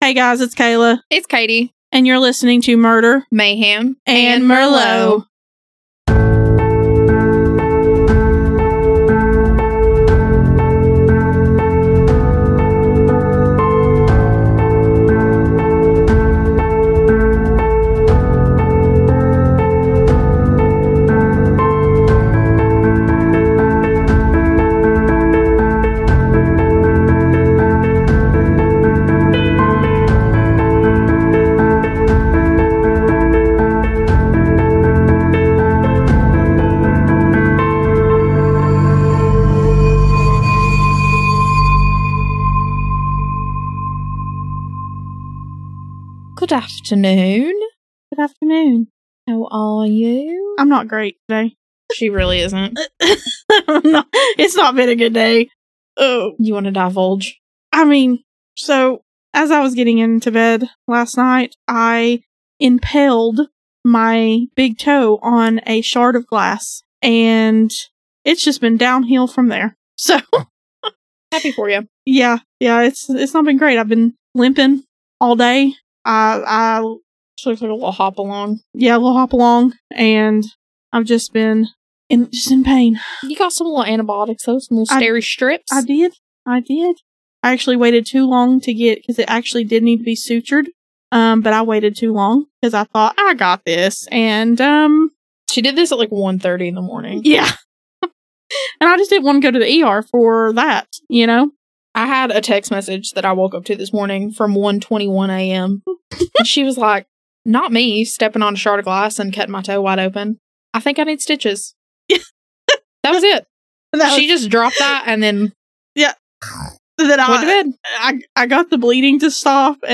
A True Crime podcast. Pour yourself a glass of your drink of choice (we're partial to wine), get comfy, and join us while we dive into new cases every week!